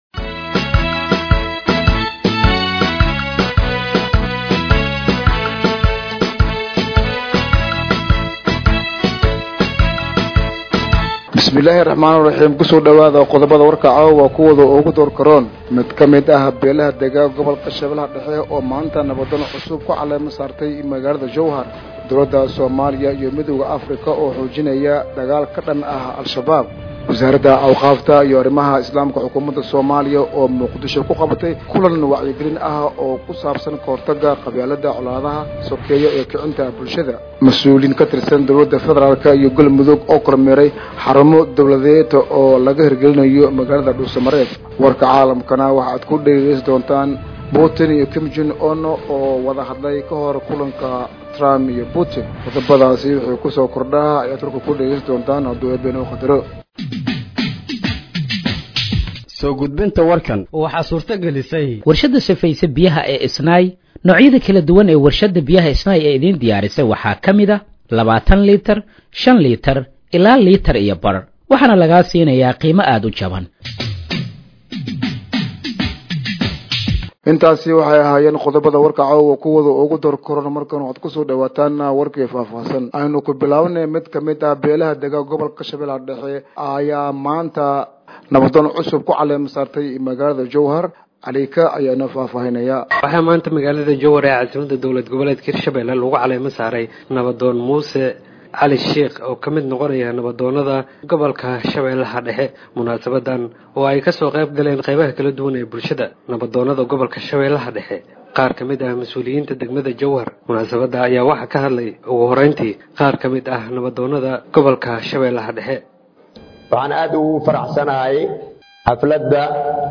Dhageeyso Warka Habeenimo ee Radiojowhar 13/08/2025